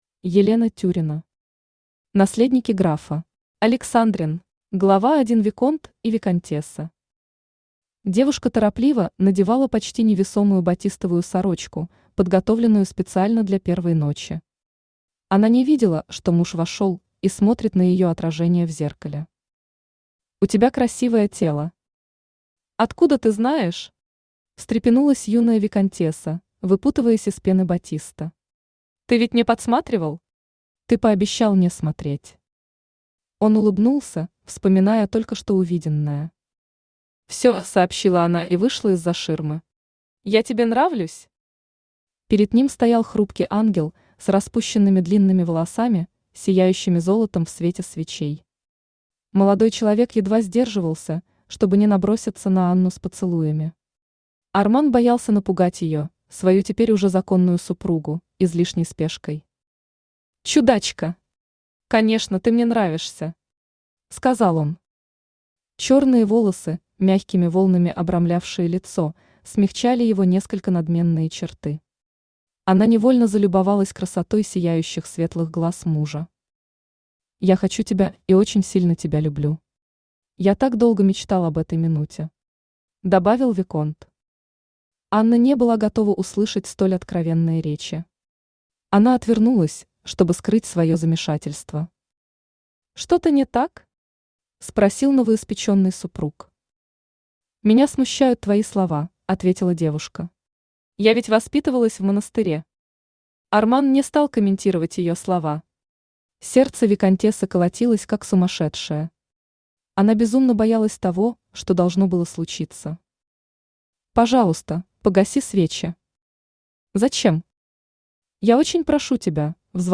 Аудиокнига Наследники графа. Александрин | Библиотека аудиокниг
Александрин Автор Елена Андреевна Тюрина Читает аудиокнигу Авточтец ЛитРес.